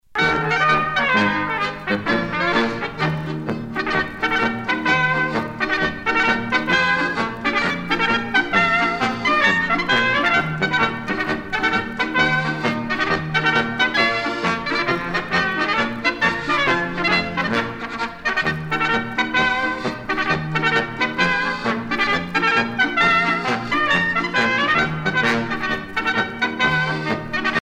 à marcher